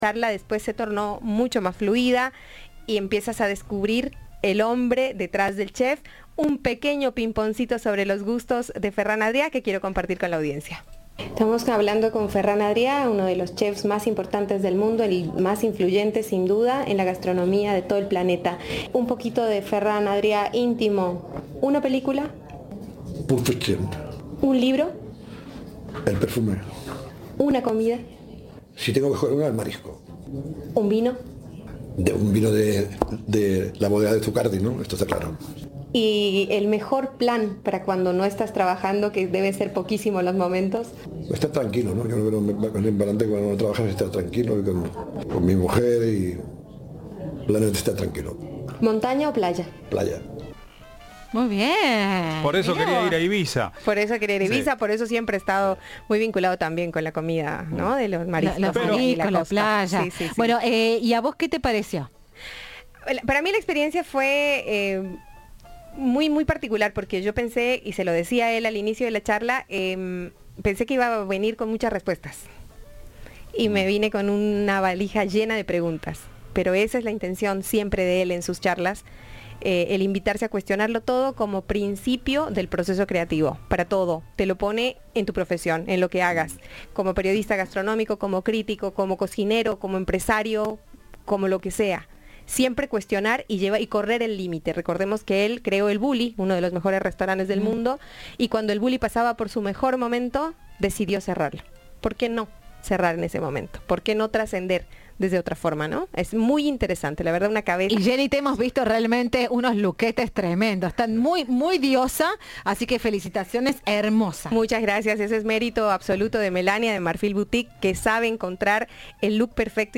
En una entrevista exclusiva con Cadena 3 Argentina, el prestigioso cocinero compartió algunos de sus gustos más personales.